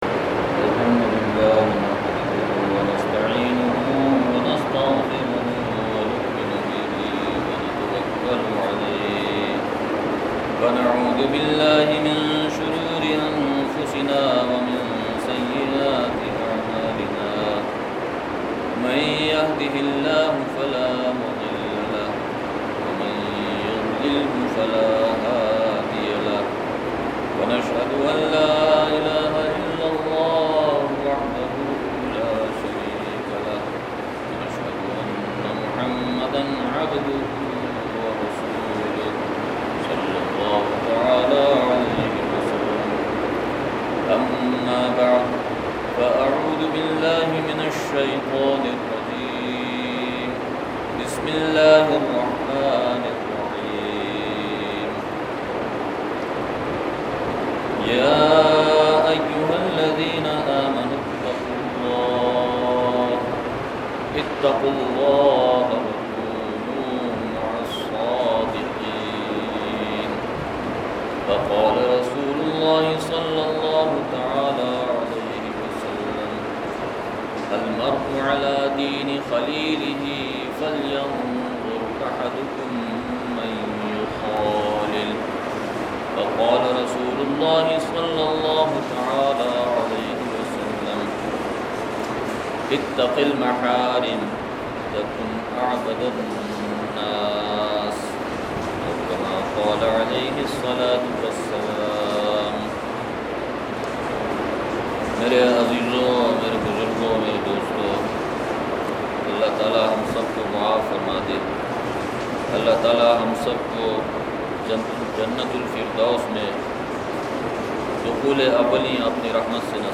جمعہ بیان – مقامی مسجد مٹہ سوات